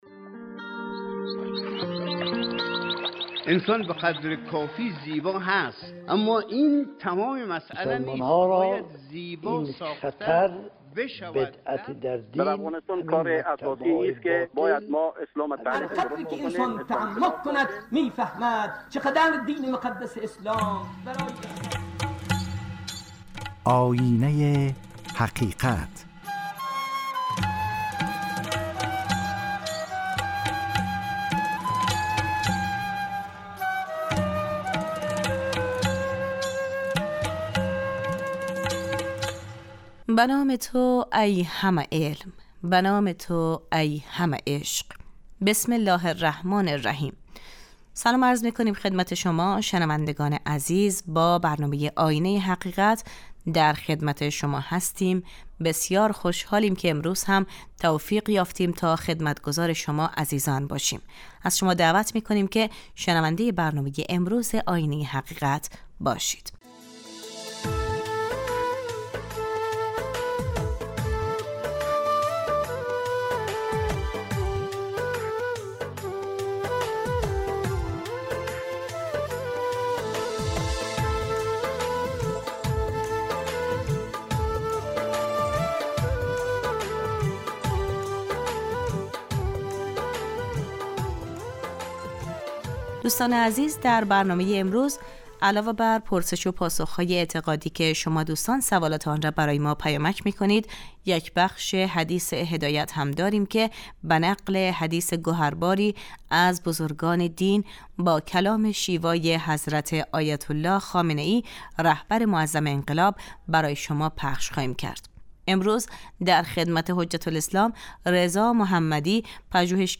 پرسش و پاسخ های اعتقادی